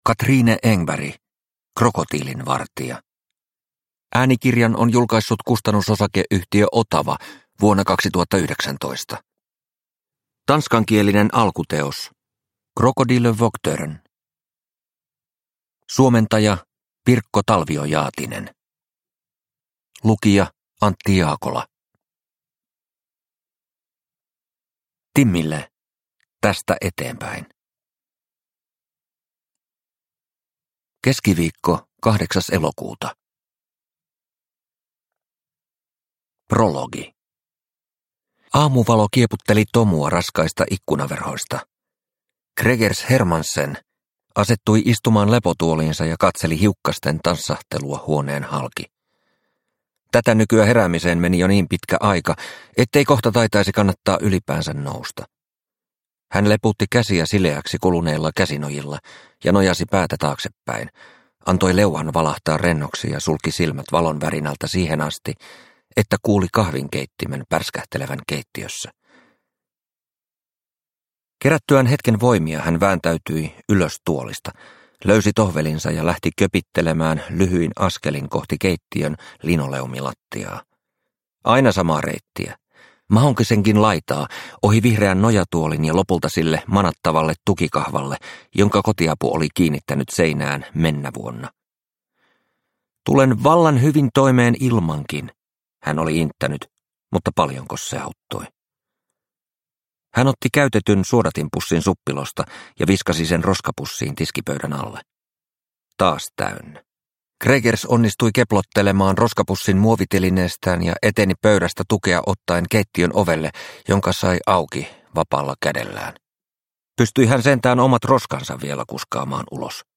Krokotiilinvartija – Ljudbok – Laddas ner